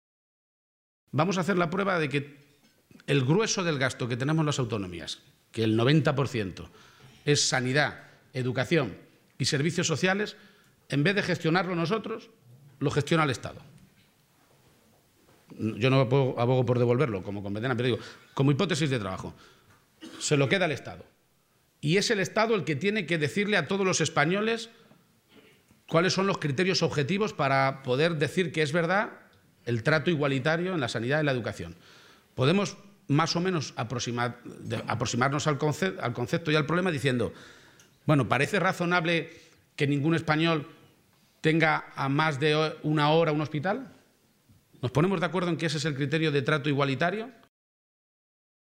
El presidente regional ha señalado hoy en Madrid, en el desayuno informativo de EuropaPress que: